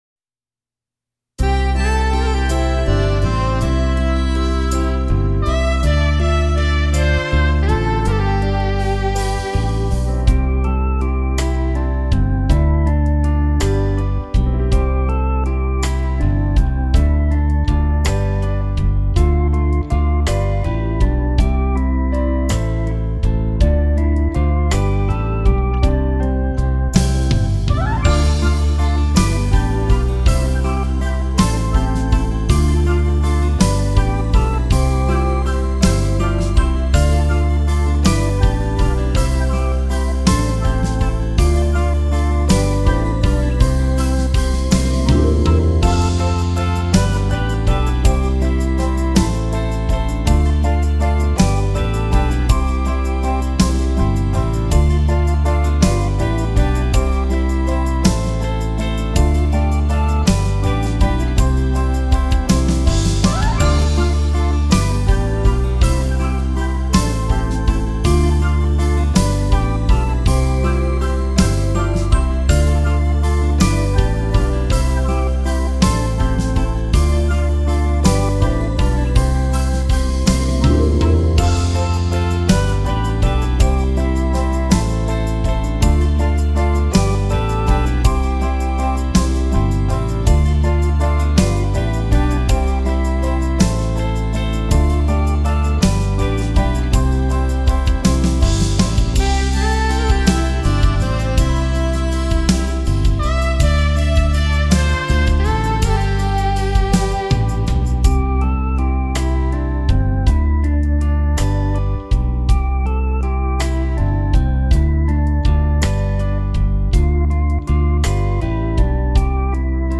Piosenka